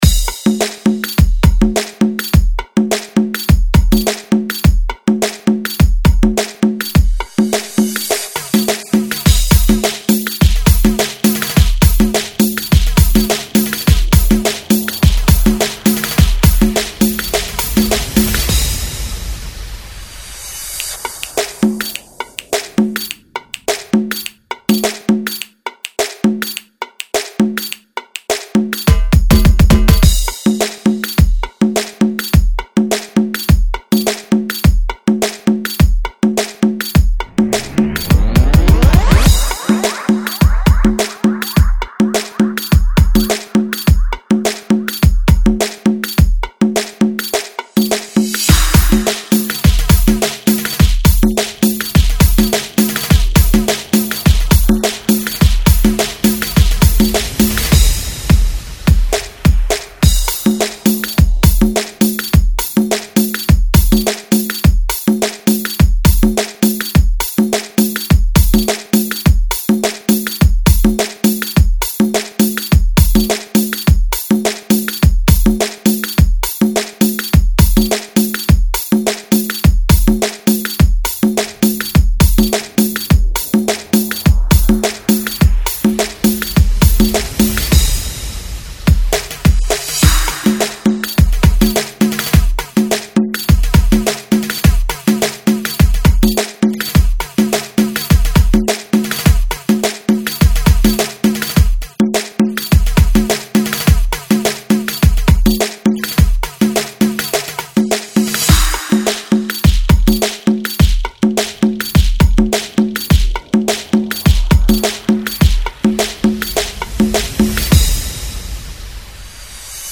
104 BPM